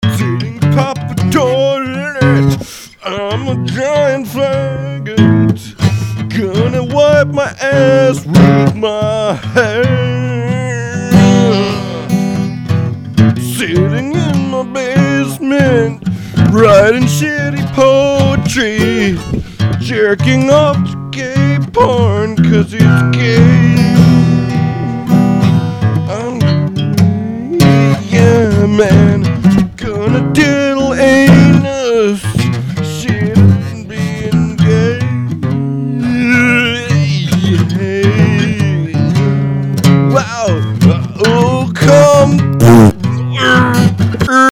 acoustic singer/songwriter